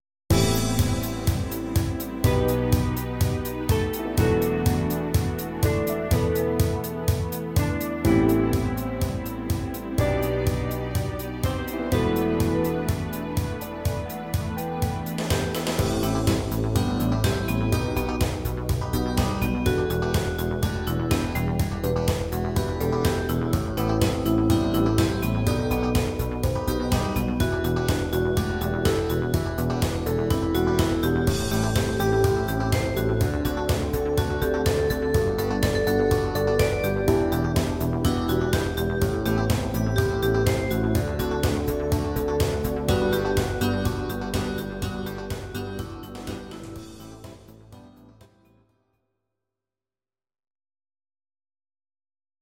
These are MP3 versions of our MIDI file catalogue.
Please note: no vocals and no karaoke included.
dance arr.